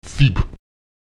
Lautsprecher cip [Tip] schnell